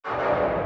HitObstacle.wav